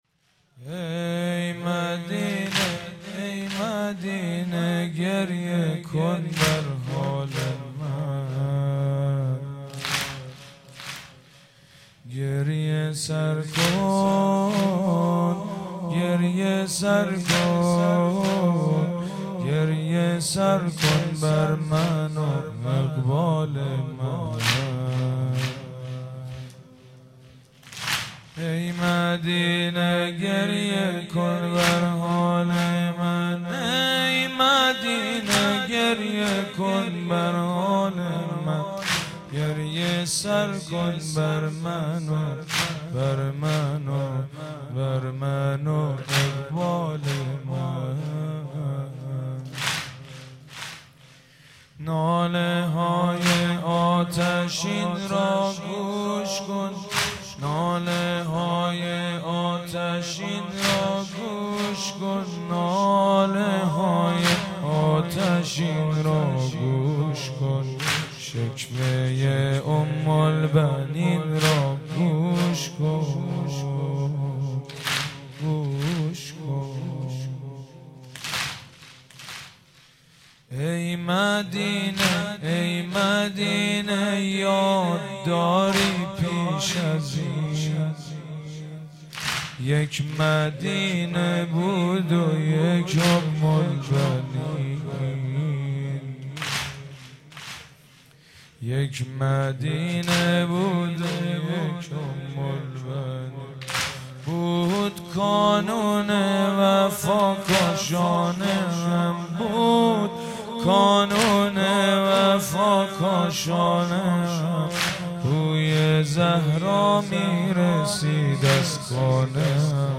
مناسبت : وفات حضرت ام‌البنین سلام‌الله‌علیها